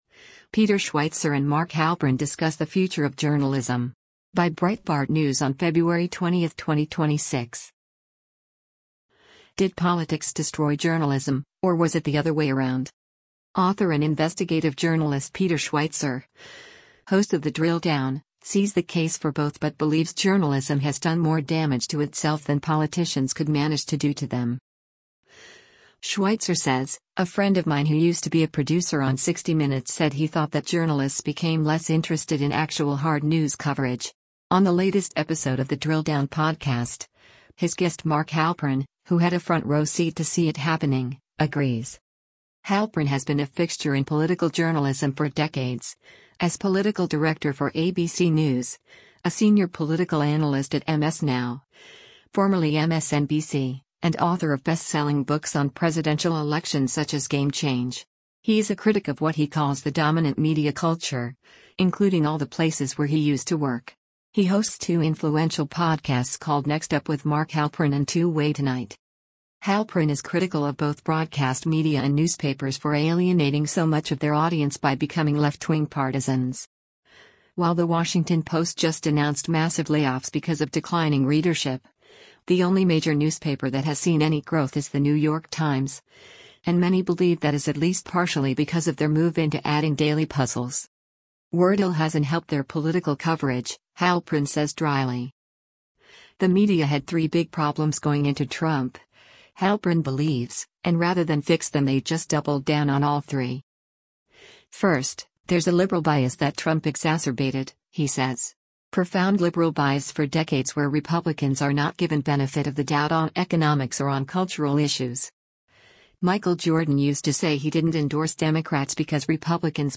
On the latest episode of The Drill Down podcast, his guest Mark Halperin, who had a front row seat to see it happening, agrees.
“Wordle hasn’t helped their political coverage,” Halperin says drily.